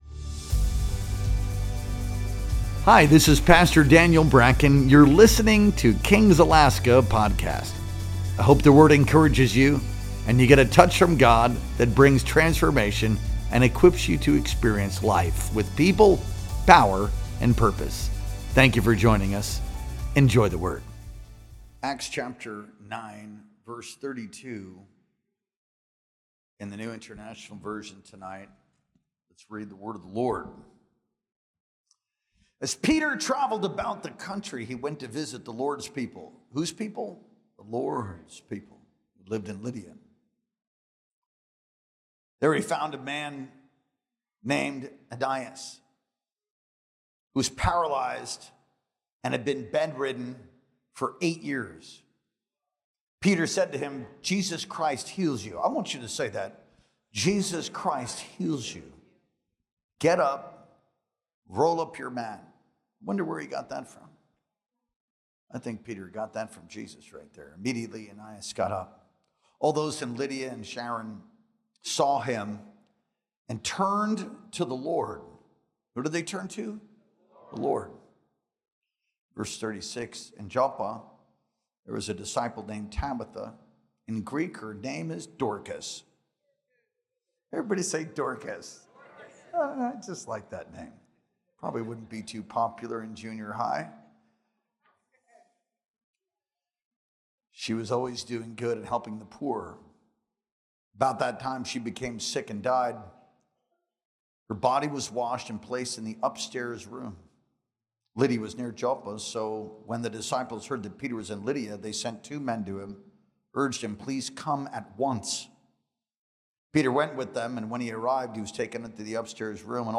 Our Wednesday Night Worship Experience streamed live on September 17th, 2025.